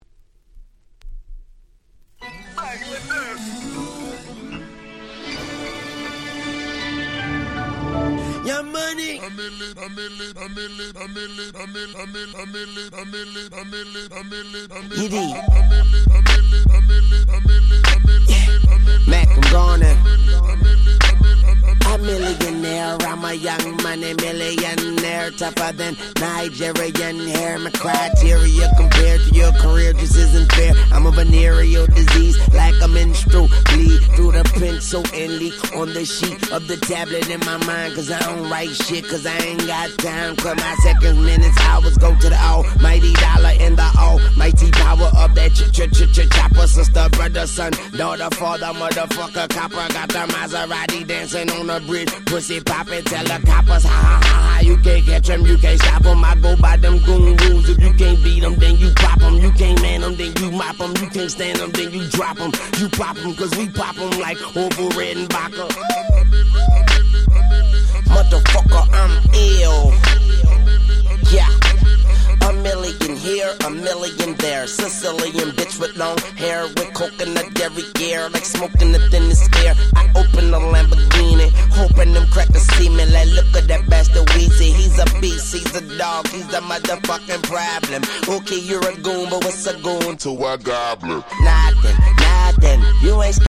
08' Super Hit Hip Hop !!